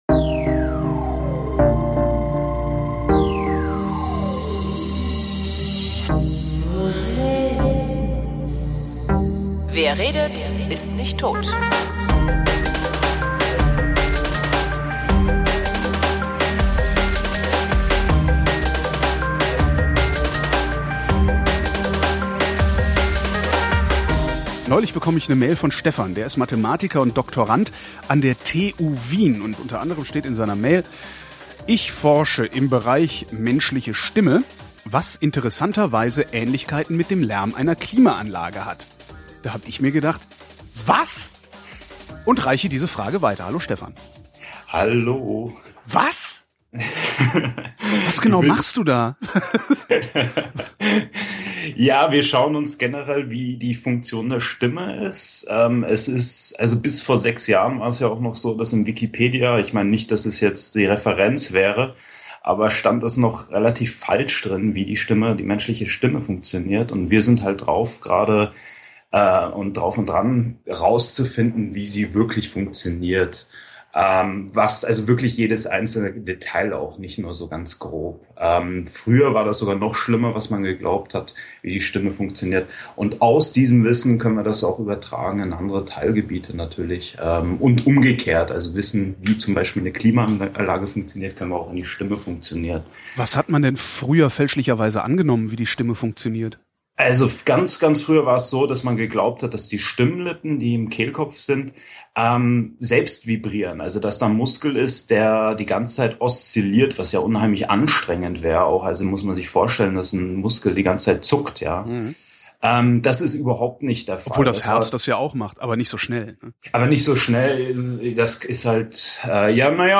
Da hab ich gleich mal angerufen, um nachzuhören, was das denn wohl bedeuten könnte.